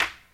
TC Clap Perc 03.wav